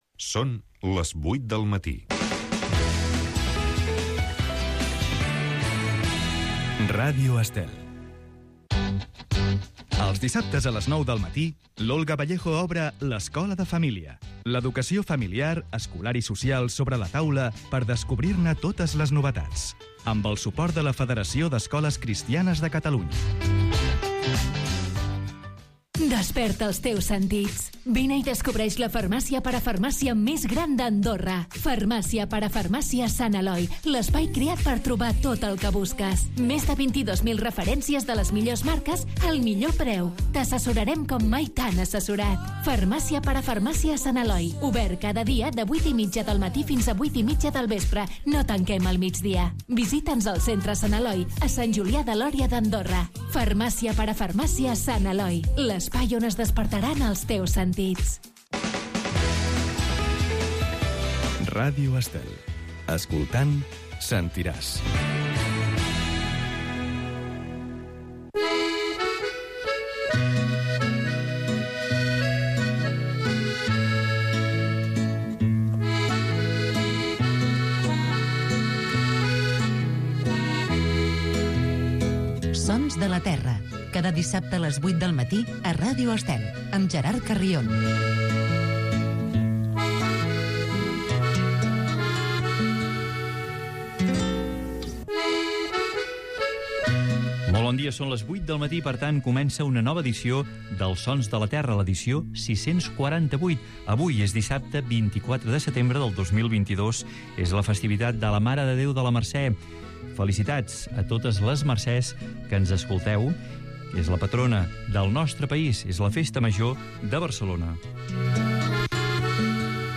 Programa dedicat al món de l’havanera.